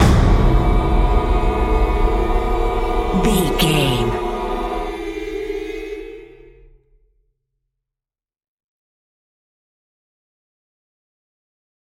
Atonal
scary
tension
ominous
dark
eerie
drums
percussion
vocals
horror